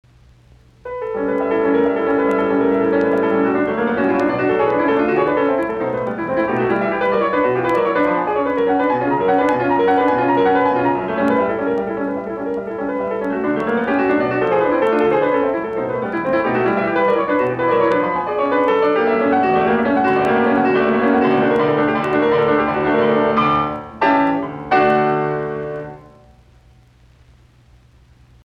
piano
No. 5 in D major, Allegro molto